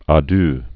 dœ)